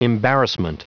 Prononciation du mot embarrassment en anglais (fichier audio)
Prononciation du mot : embarrassment